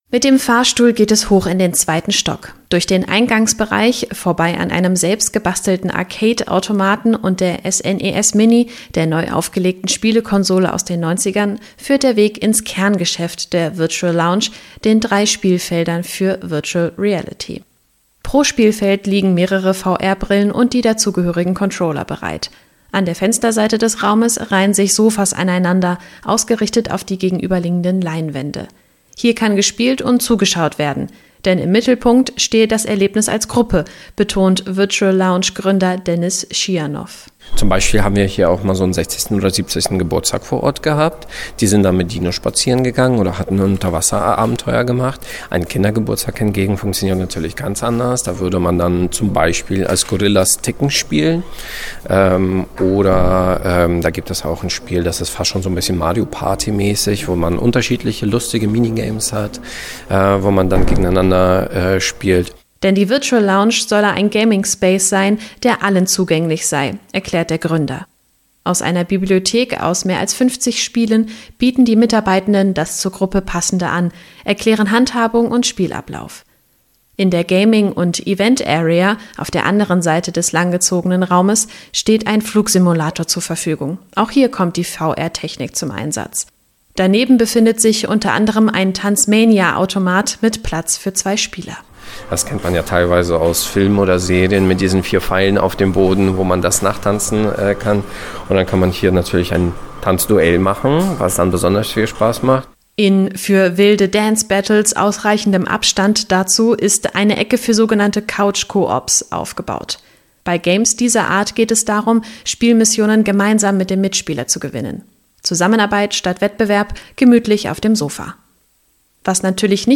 Zocken, Gaming, Freunde finden: Hausbesuch in der VirtuaLounge in Braunschweig - Okerwelle 104.6